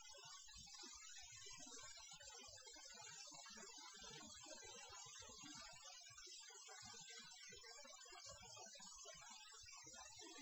Here is a sample of the noise after I used noise reduction.
Typical sound artifacts of too much noise reduction.
noise.wav